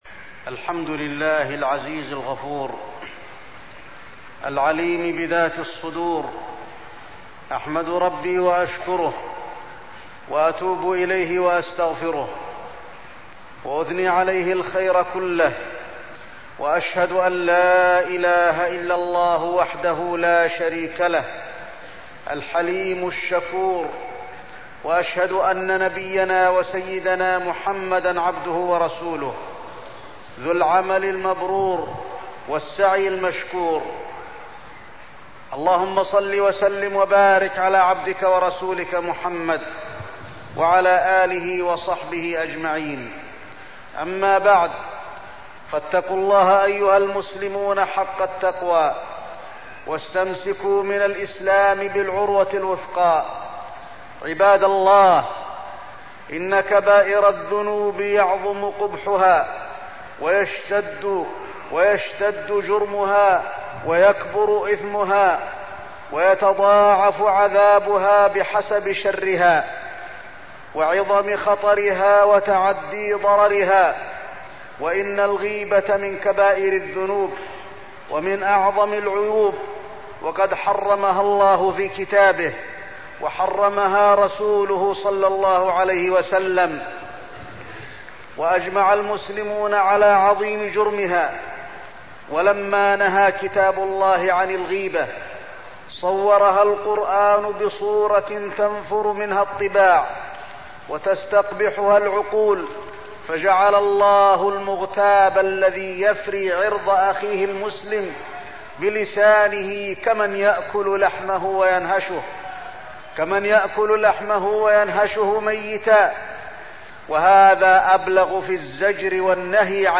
تاريخ النشر ١٦ صفر ١٤١٦ هـ المكان: المسجد النبوي الشيخ: فضيلة الشيخ د. علي بن عبدالرحمن الحذيفي فضيلة الشيخ د. علي بن عبدالرحمن الحذيفي الغيبة